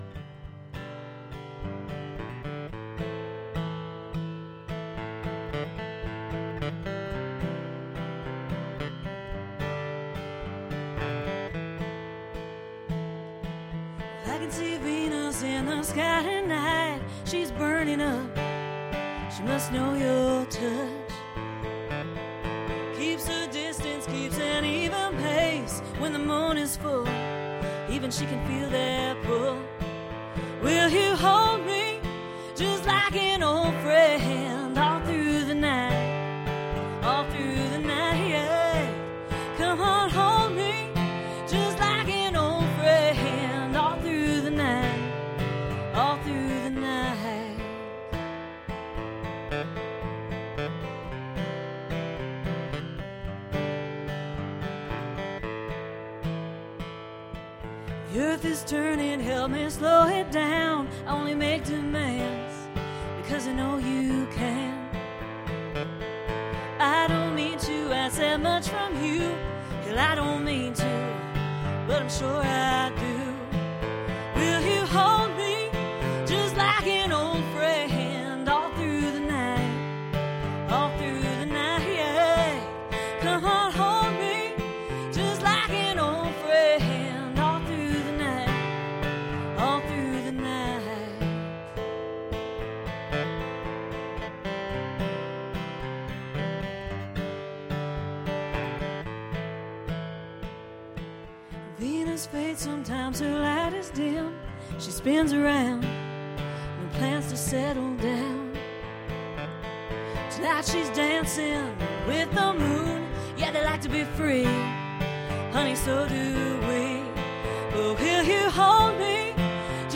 But this a live radio show, and one of my favorites.